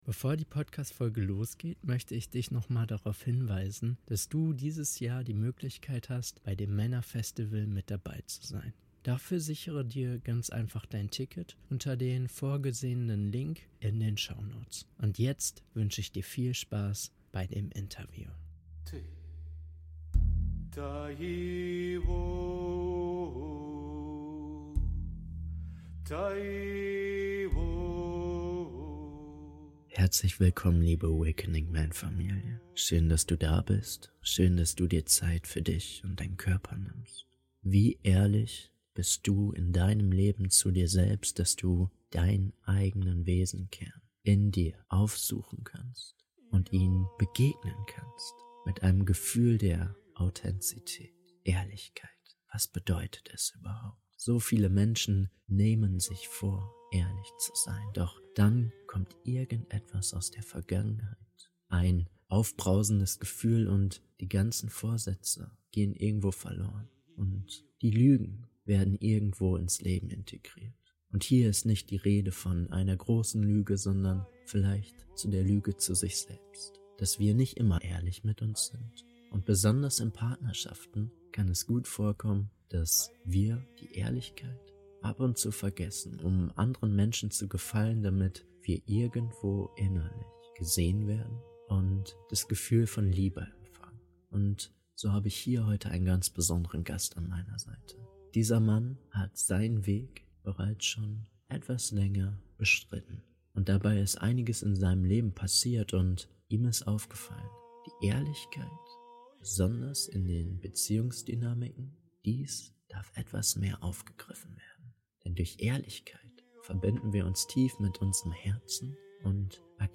Radikale Ehrlichkeit - Interview